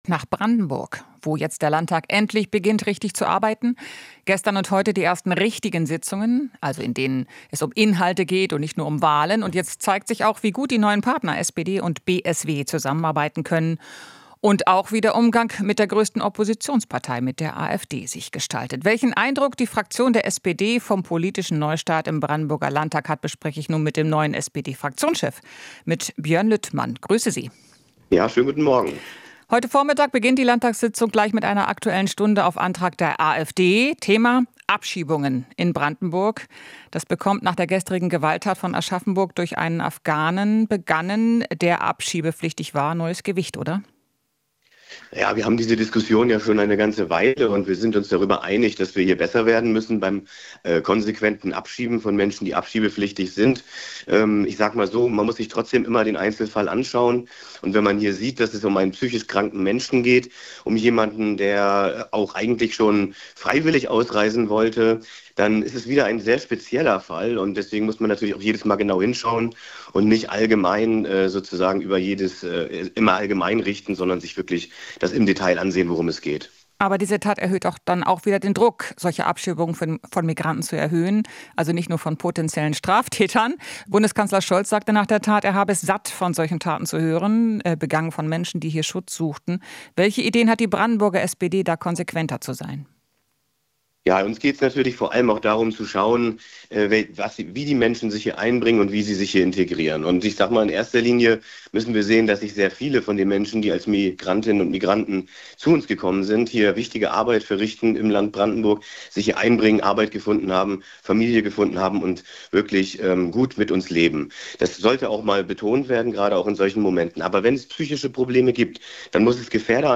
Interview - Lüttmann (SPD): Müssen besser werden beim konsequenten Abschieben